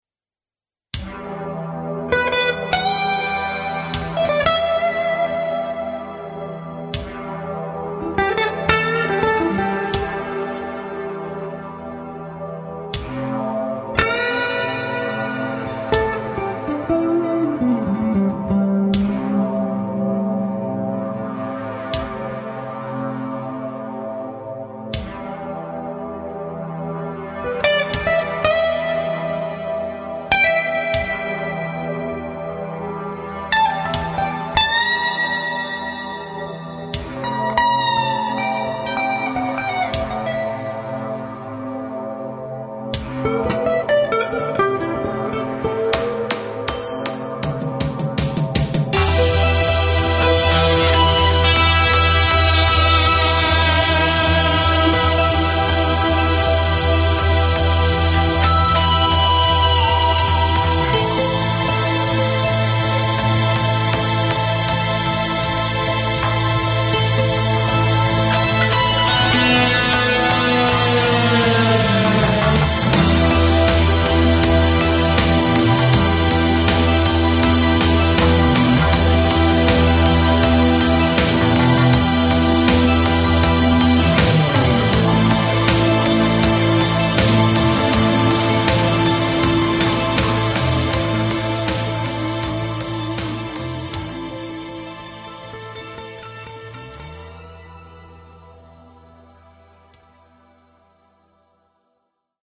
percussioni
batteria
tastiere
basso
chitarre - tastiere